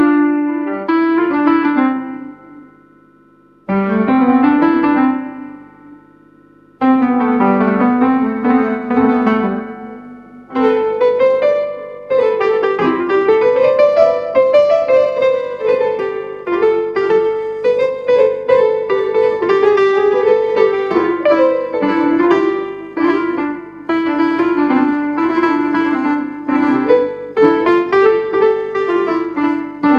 arabic-music audio-to-audio music-generation
Generate Arab Maqam Melodic Improvisations (Taqasim)